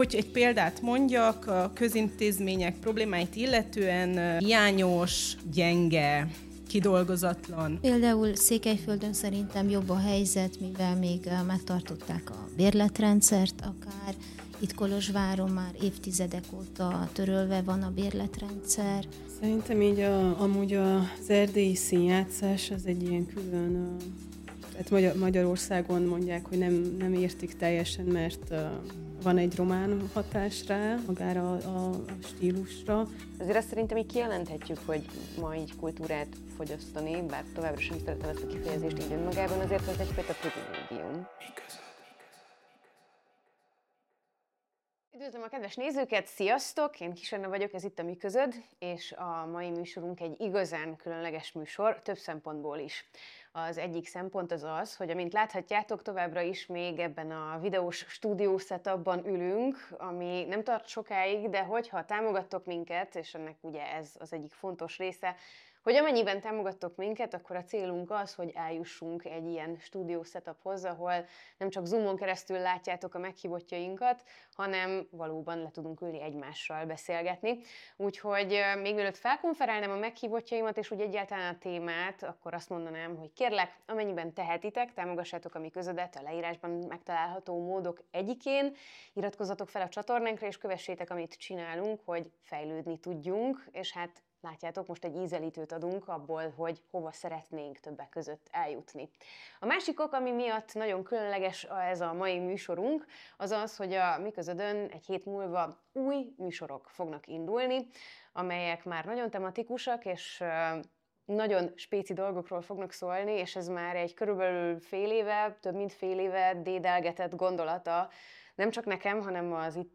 Foglalkoznunk kell a társadalom, a rendszer fasizálódásával, annál is inkább, hogy az „atlantista erők” által képviselt neoliberális politika, a minimális állam-koncepció nem megoldani, hanem inkább súlyosbítani fogja a helyzetet. Annak érdekében, hogy a cselekvést helyezzük középpontba olyan szervezeteket hívunk meg egy beszélgetésre, akik alulról szerveződve valamilyen társadalmi célú tevékenységet végeznek, ezáltal próbálnak egy demokratikusabb világot építeni úgy, hogy néha az állam feladatát is ellátják.